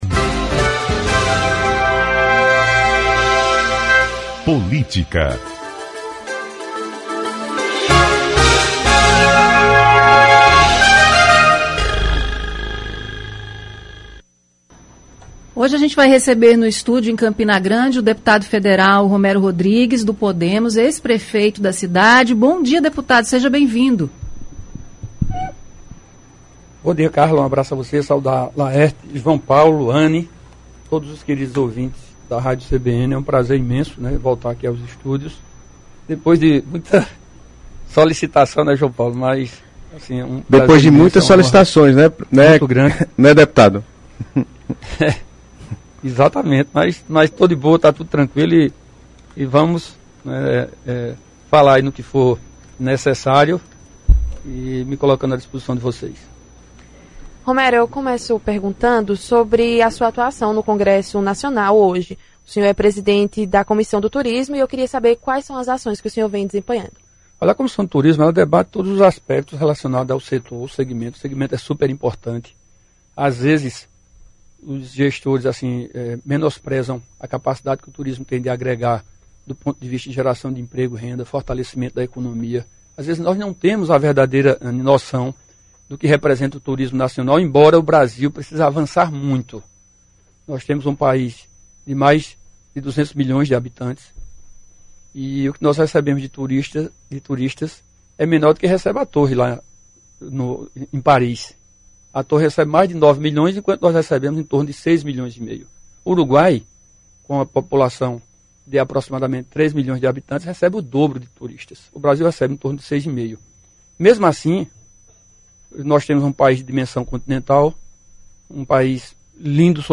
Entrevista: Romero Rodrigues fala sobre relações com Tovar e Pedro – CBN Paraíba
O deputado federal Romero Rodrigues foi o entrevistado desta segunda-feira (16) no Momento da Política.